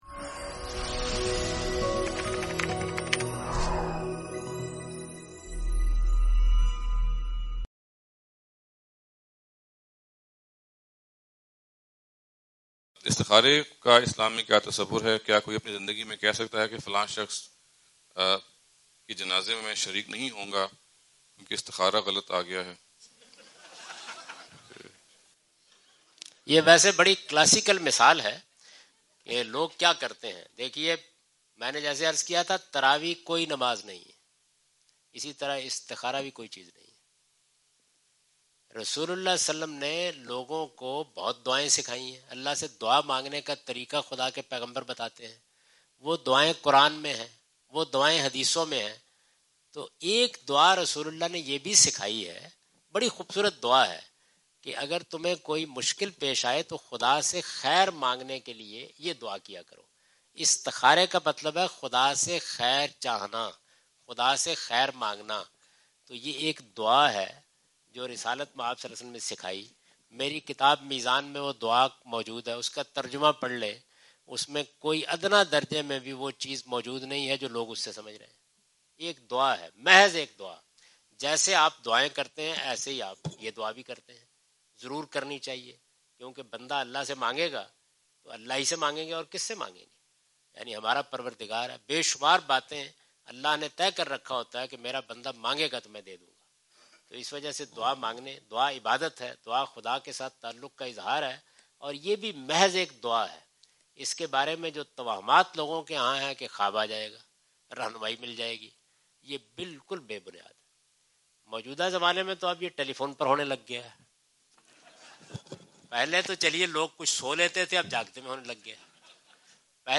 Category: English Subtitled / Questions_Answers /
جاوید احمد غامدی اپنے دورہ امریکہ 2015 کے دوران سانتا کلارا، کیلیفورنیا میں "اسلام میں استخارہ کی اہمیت" سے متعلق ایک سوال کا جواب دے رہے ہیں۔